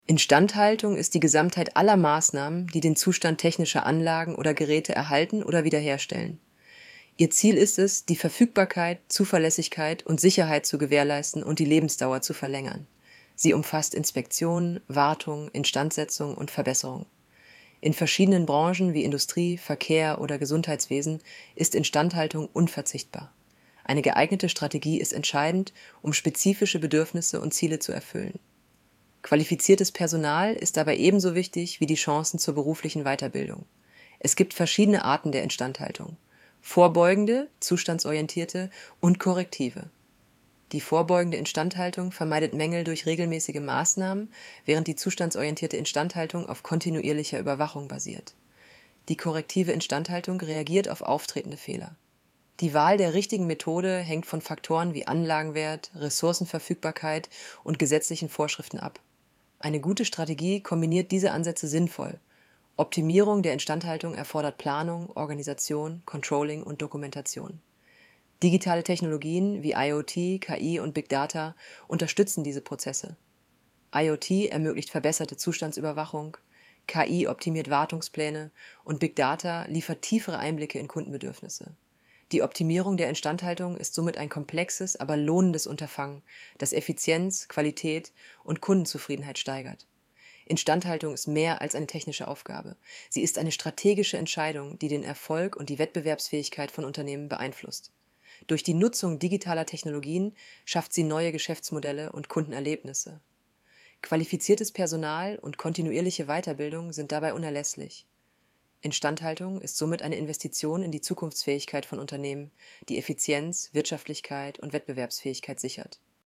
Hinweis: Diese Vorlesefunktion verwendet eine synthetisch erzeugte Stimme aus einem KI-System.Die Stimme ist keine Aufnahme einer realen Person.